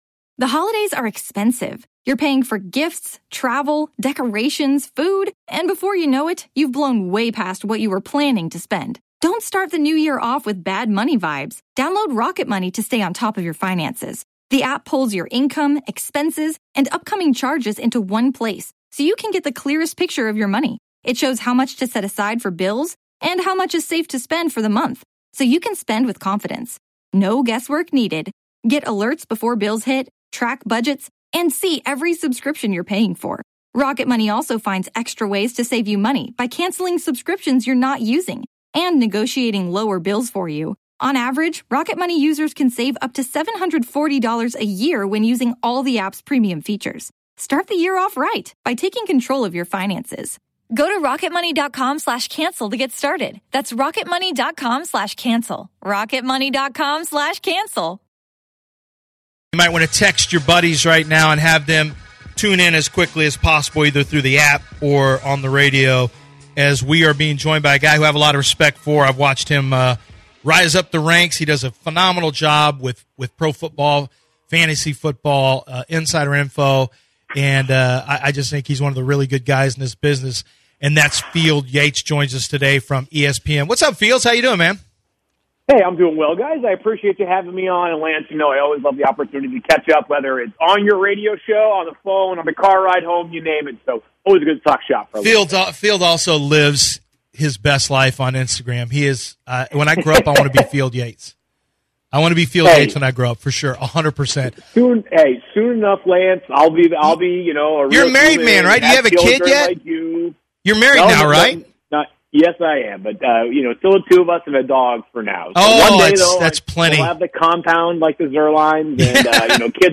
ESPN's Field Yates joins the show to discuss the hiring of Nick Caserio